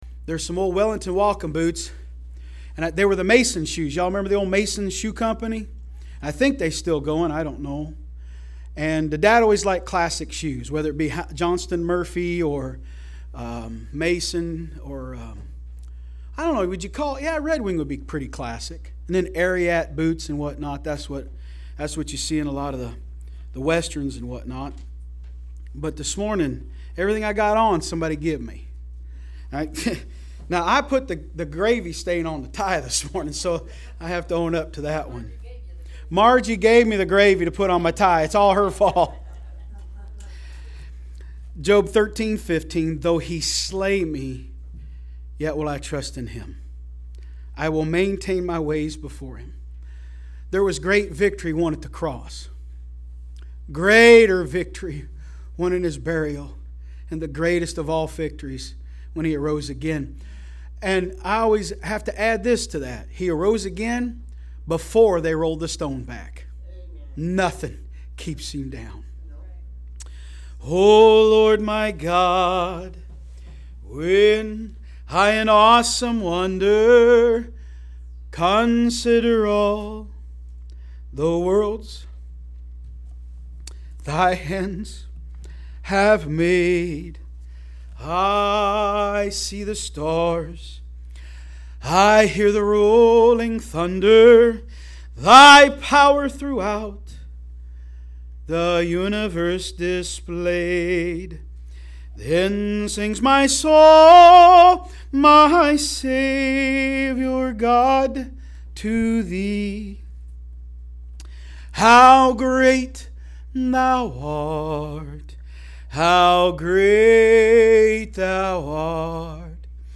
From Series: "AM Service"